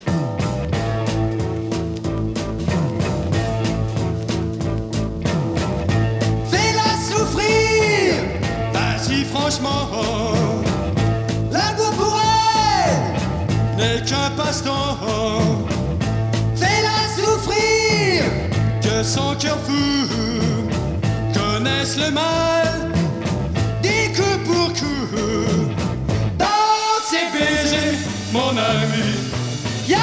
Beat rock